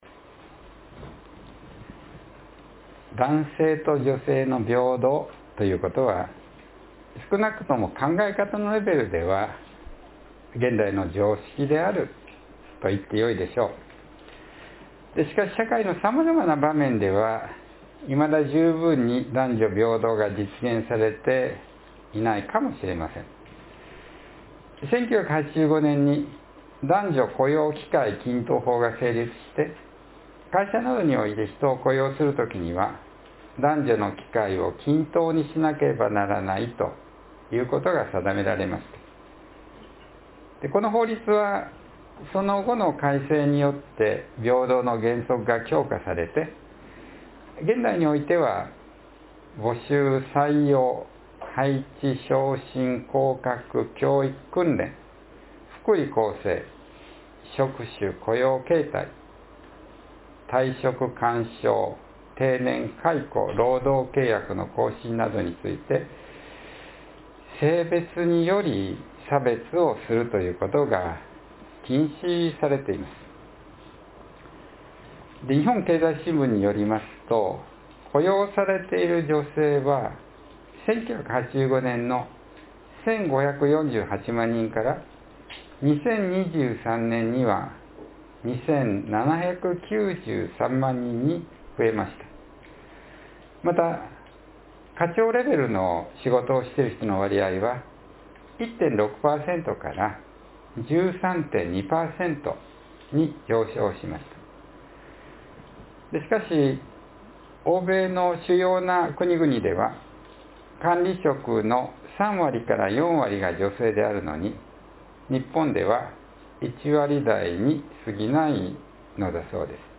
（3月8日の説教より）